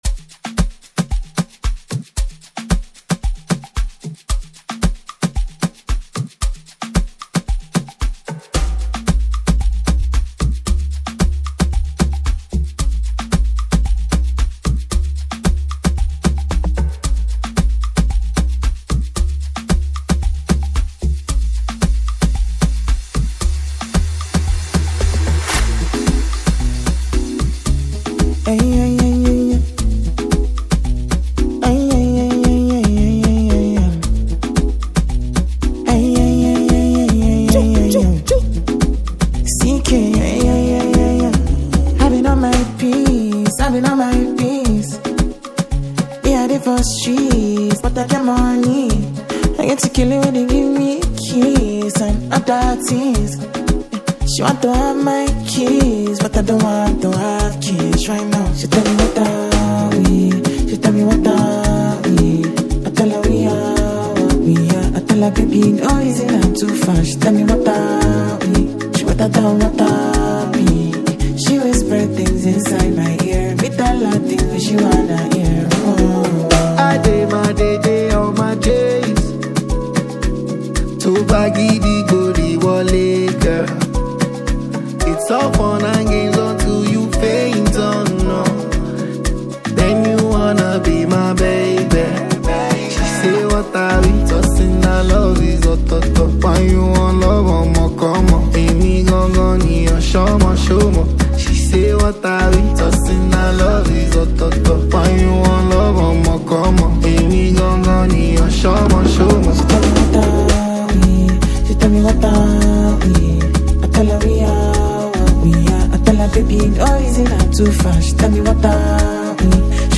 Buzzing fast-rising Nigerian singer